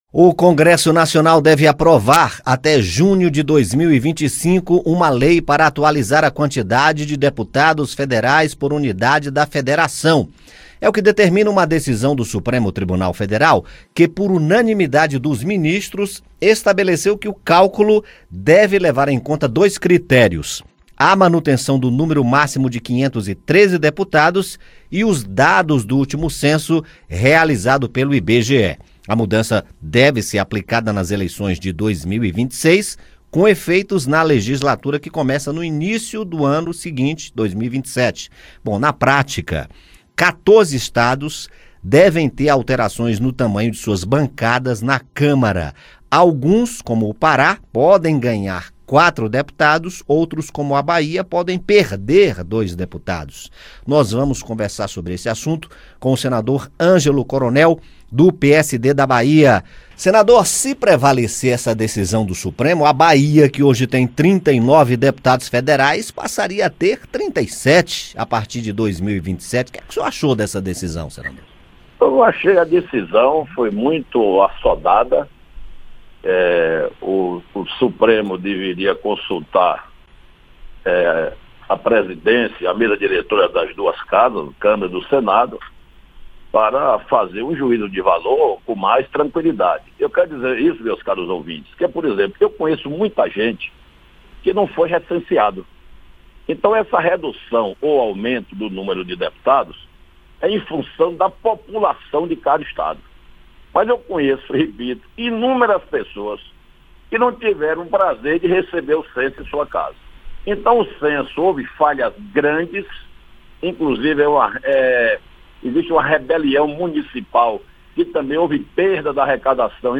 O senador Angelo Coronel (PSD-BA) fala sobre a decisão, o novo critério para o cálculo do número máximo de 513 e como as alterações afetam o tamanho das bancadas na Câmara. Angelo Coronel faz críticas à decisão e aponta necessidade de uma solução mais viável, como a realização de novo Censo.